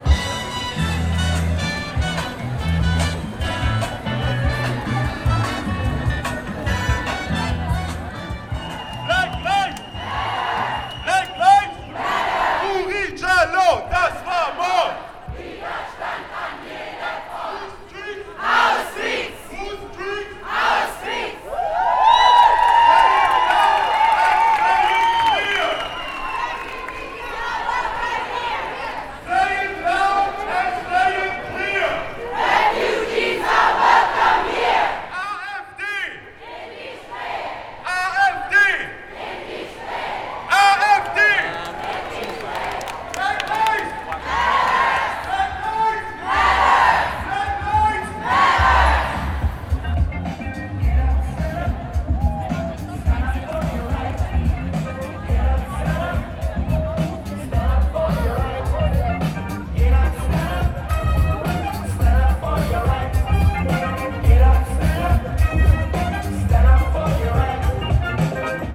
Hier außerdem ein paar akustische Eindrücke von der Demo:
Atmo-Black-Lives-Matter.mp3